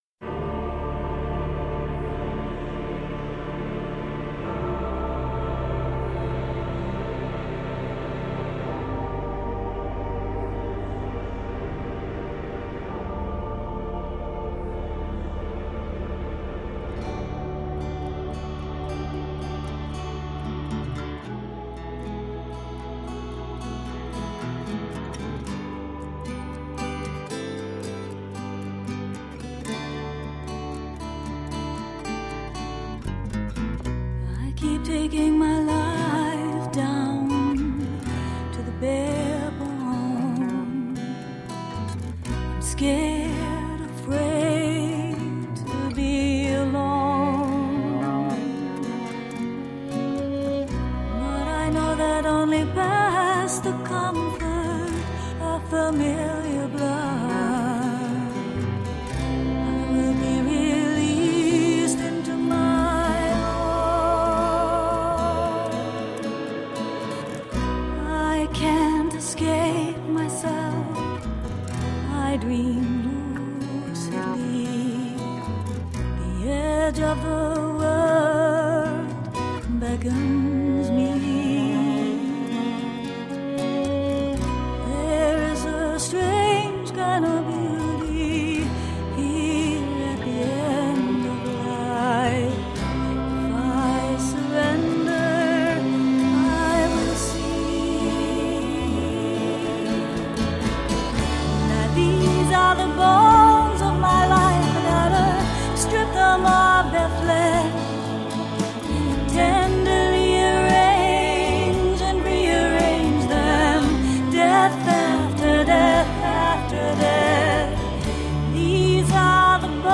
Introspective avant-pop songs.
Tagged as: Alt Rock, Rock, Woman Singing Electro Pop